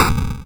spike_trap_b.wav